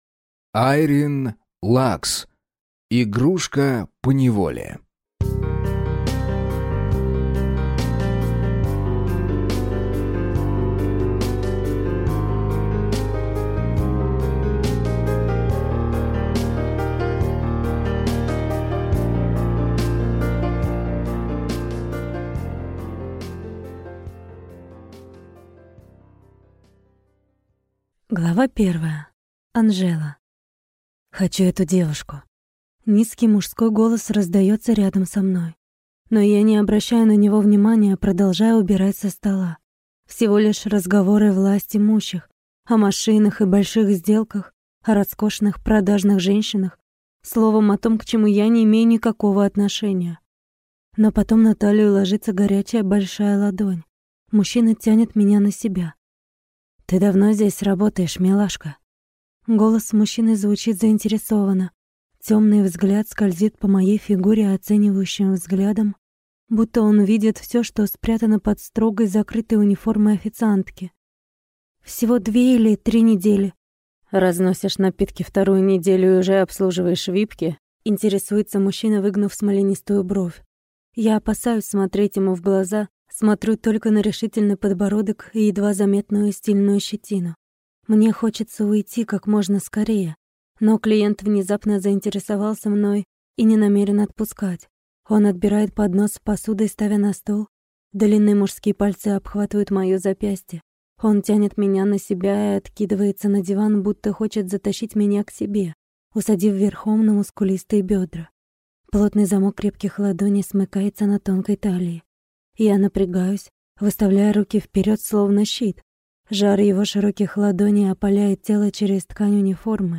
Аудиокнига Игрушка поневоле | Библиотека аудиокниг